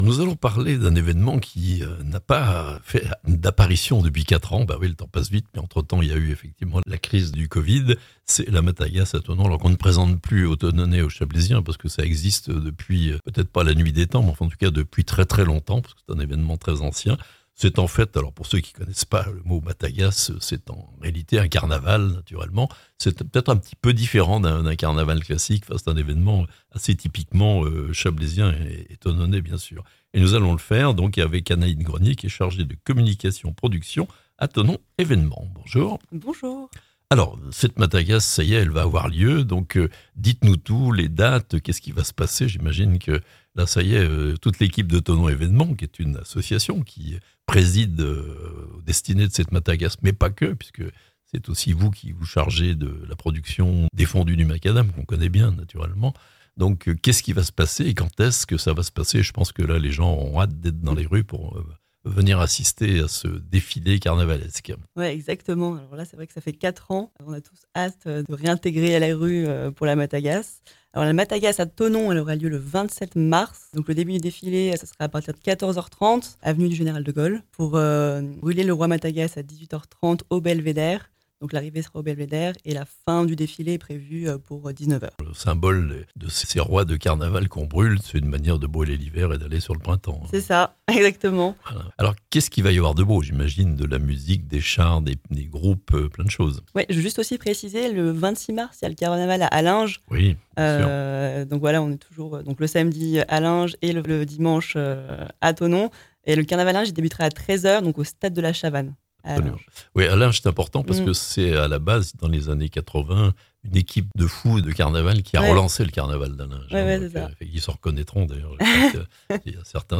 Après 4 ans d'absence, le retour de la Matagasse à Thonon (interview)